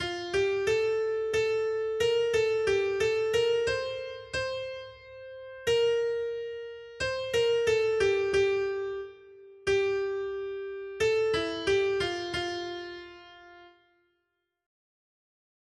Noty Štítky, zpěvníky ol529.pdf responsoriální žalm Žaltář (Olejník) 529 Skrýt akordy R: Veliká jsou Hospodinova díla. 1.